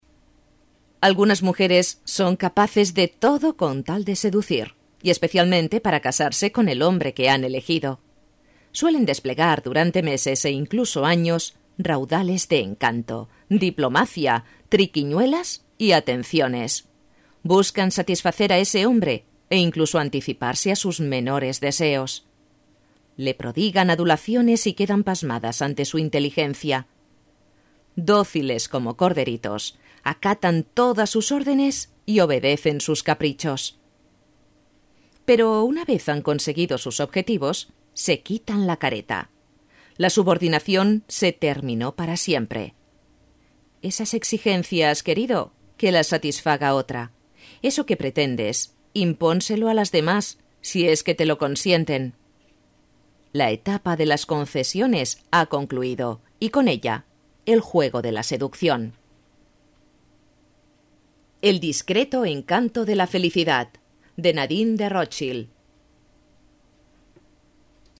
Voz media y joven.
kastilisch
Sprechprobe: Werbung (Muttersprache):